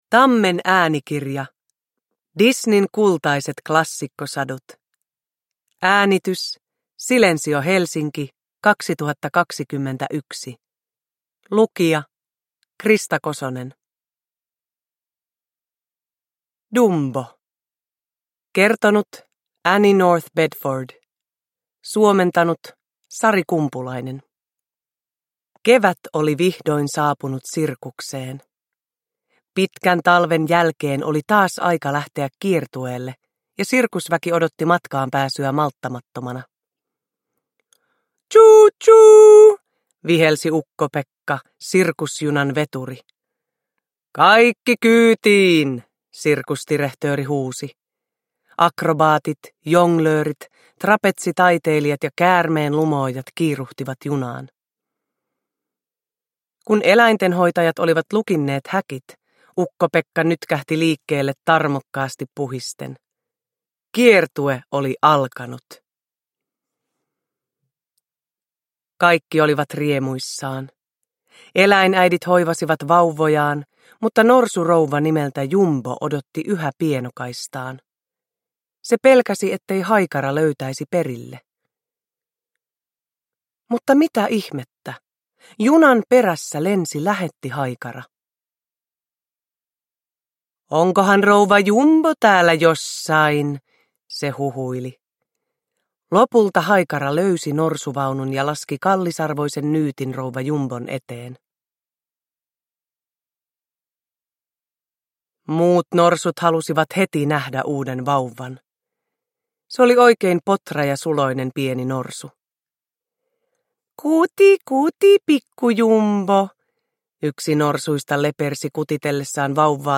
Disneyn kultaiset klassikkosadut – Ljudbok – Laddas ner
Uppläsare: Krista Kosonen